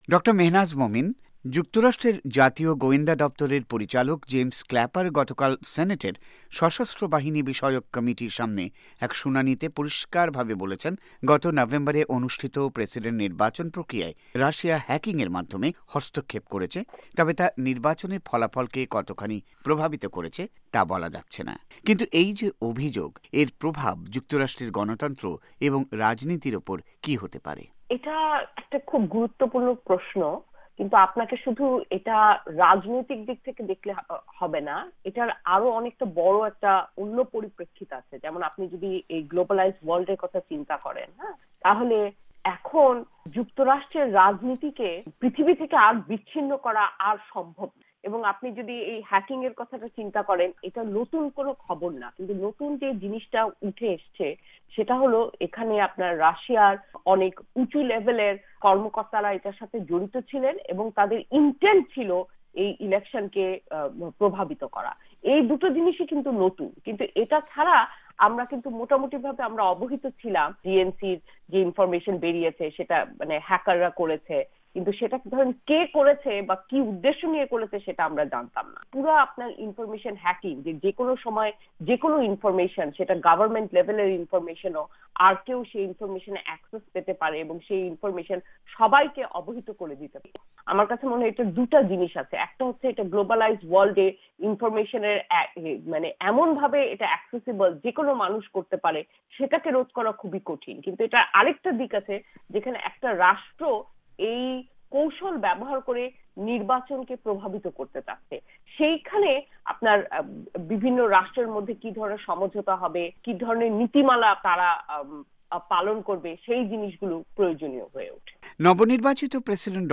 যুক্তরাষ্ট্র নির্বাচনে রুশ হ্যাকিং ও ডনাল্ড ট্রাম্পের প্রতিক্রয়া প্রসঙ্গ: সাক্ষাৎকার